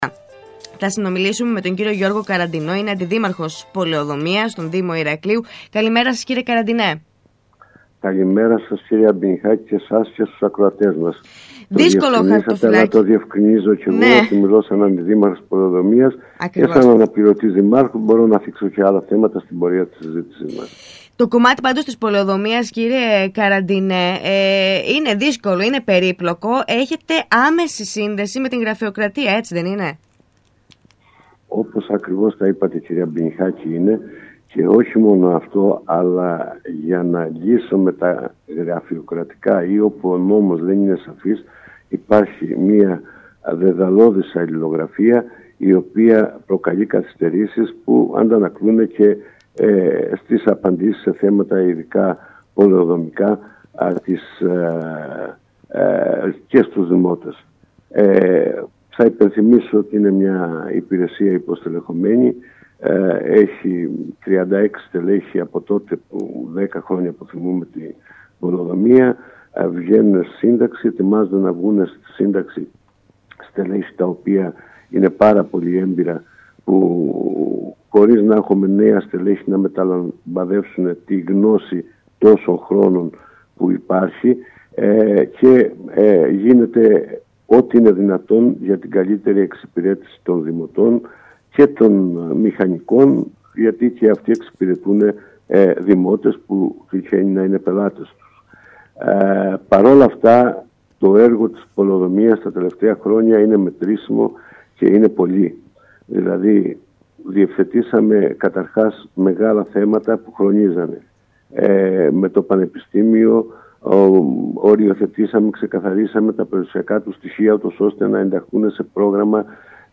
Στα έργα και τις παρεμβάσεις που έχουν προχωρήσει στο «χαρτοφυλάκιο» της Πολεοδομίας στον Δήμο Ηρακλείου αλλά και στις δυσκολίες που προκύπτουν εξαιτίας της γραφειοκρατίας μίλησε στον ΣΚΑΪ Κρήτης